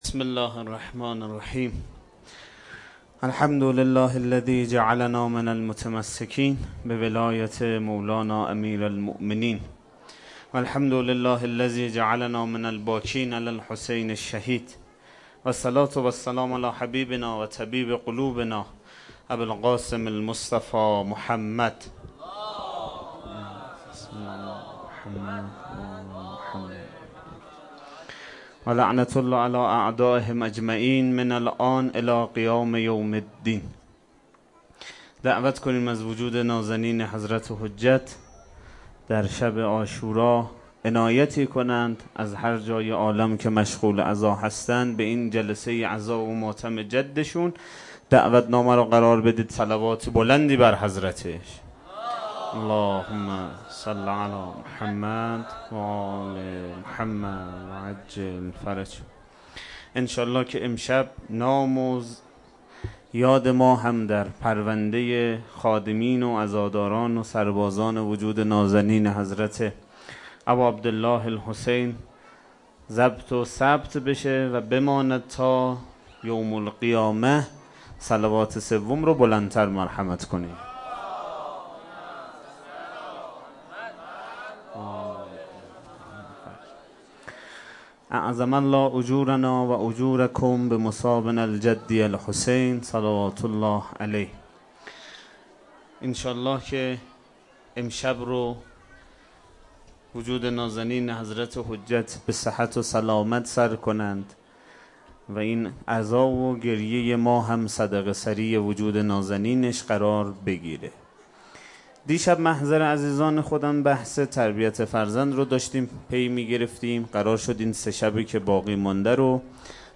سخنرانی شب عاشورا98